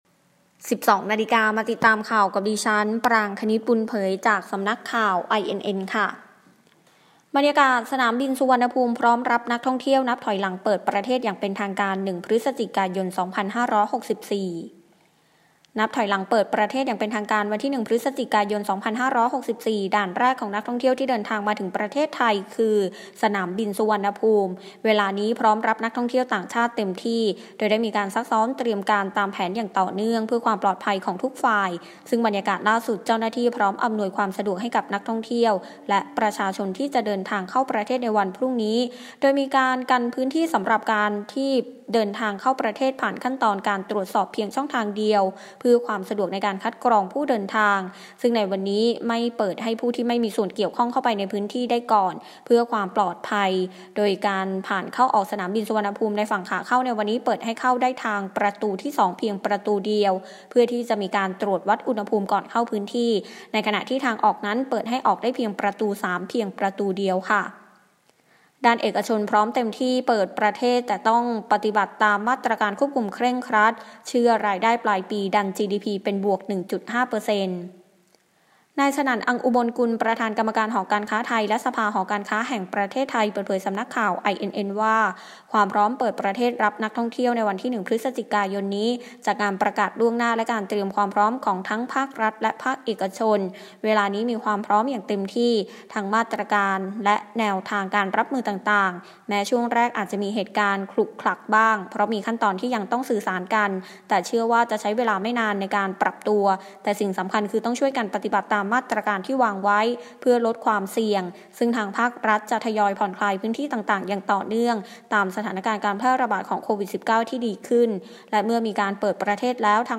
ข่าวต้นชั่วโมง 12.00 น.
บรรยากาศสนามบินสุวรรณภูมิ พร้อมรับนักท่องเที่ยว นับถอยหลังเปิดประเทศอย่างเป็นทางการ 1 พฤศจิกายน 2564